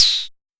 Modular Snare 03.wav